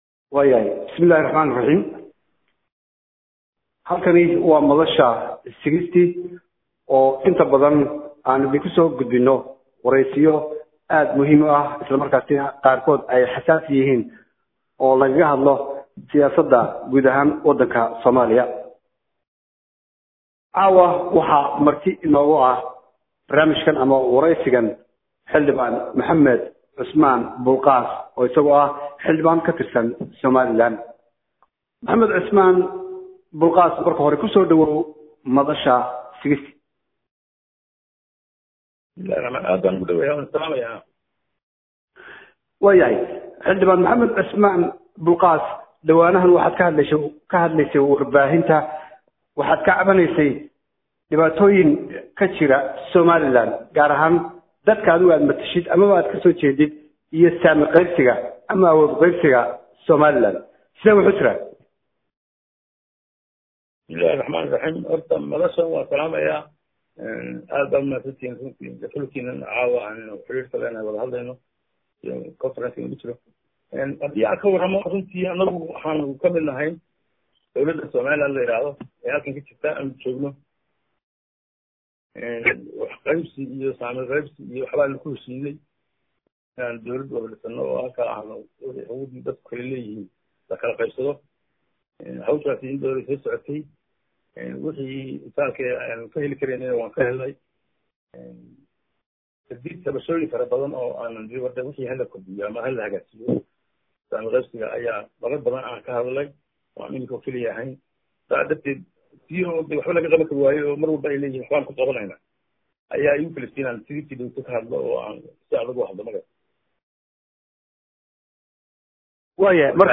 Warysigan oo dhinacyo badan taabanayay ayaa waxa uu muu jinayay heerka masiireed ee ay ka taagan yihiin Siyaasiyiinta Harti ama Samaroon ee ku dhex milmay Maamulka Somaliland.